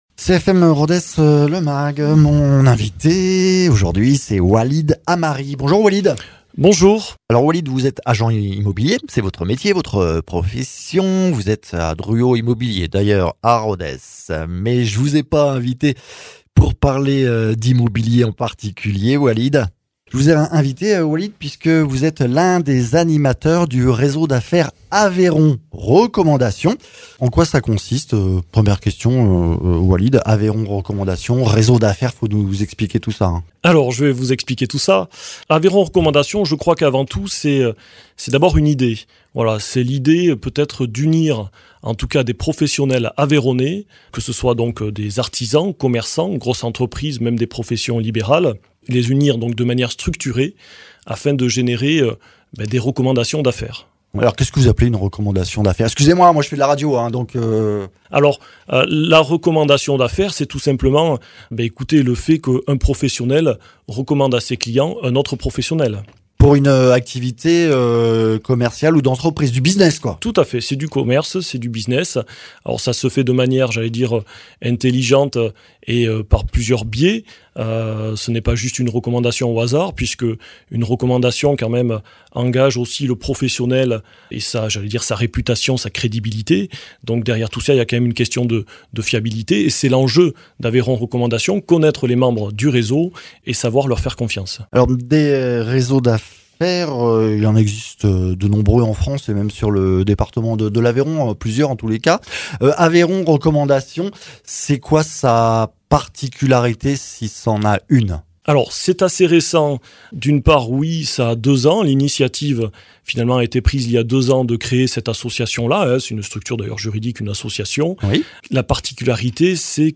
comportementaliste félin